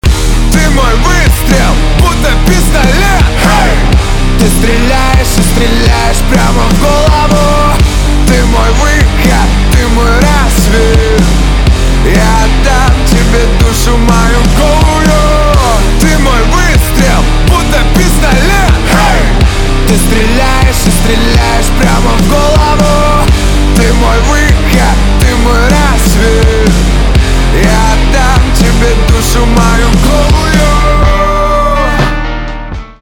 русский рок
гитара , барабаны , чувственные